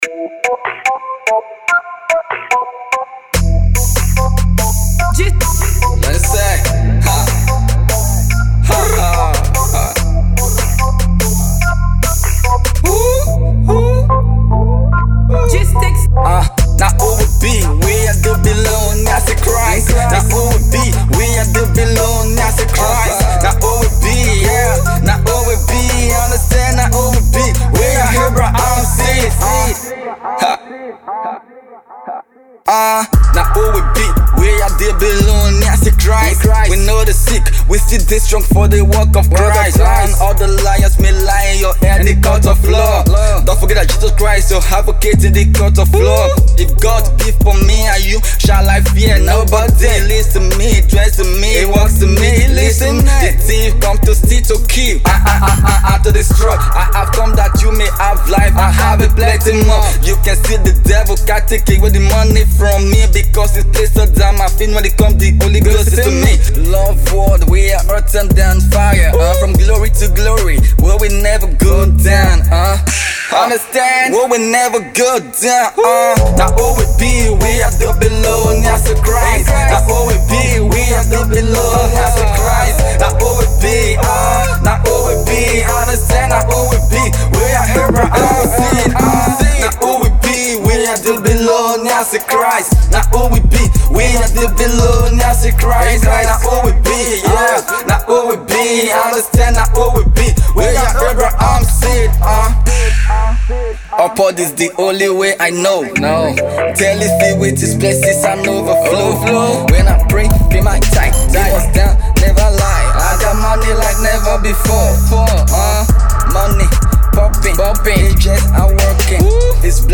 Christian Hip Hop Artiste